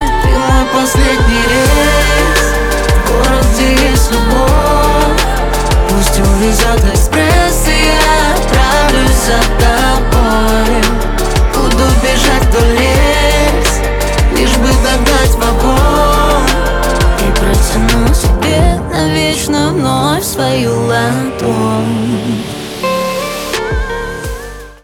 RnB
Поп Танцевальные